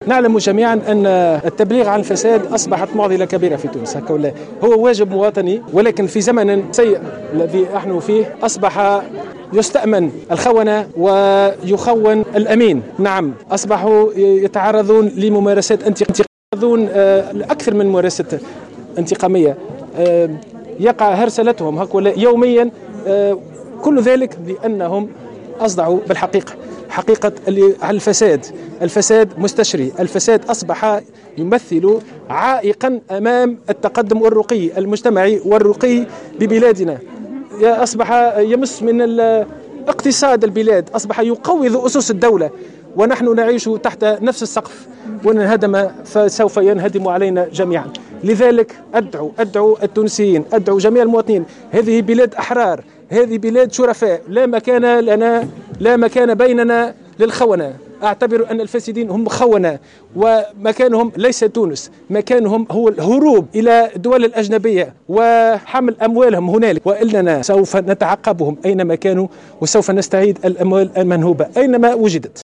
قال رئيس هيئة مكافحة الفساد عماد بوخريص في تصريح لمراسلة الجوهرة اف ام اليوم...